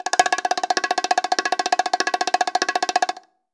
Index of /90_sSampleCDs/AKAI S6000 CD-ROM - Volume 5/Cuba2/STEREO_BONGO_2
F BNG ROLL-S.WAV